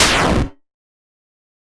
lose-life.ogg